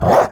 Minecraft Version Minecraft Version 1.21.5 Latest Release | Latest Snapshot 1.21.5 / assets / minecraft / sounds / mob / wolf / angry / bark3.ogg Compare With Compare With Latest Release | Latest Snapshot
bark3.ogg